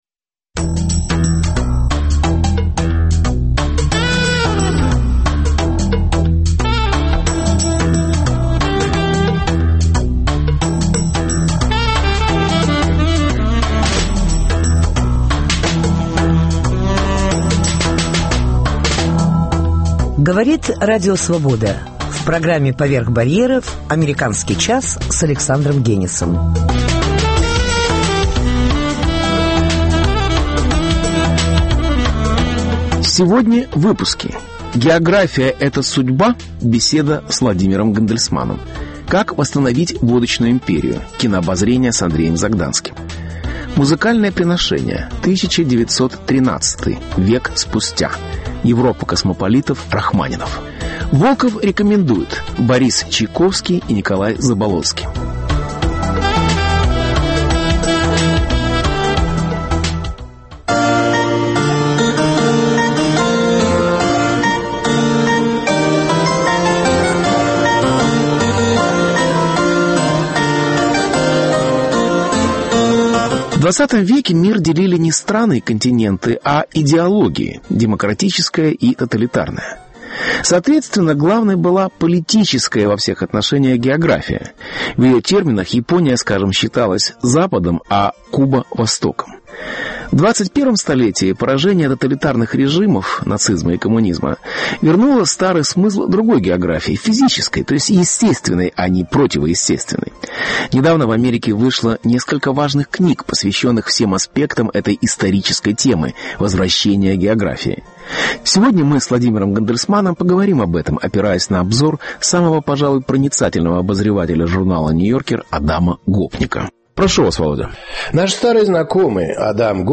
География - это судьба? (Беседа